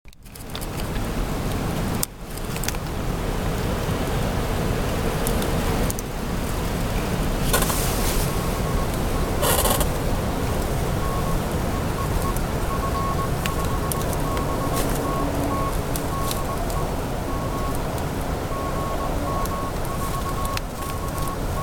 И запись, как шумит ветер вне вигвама.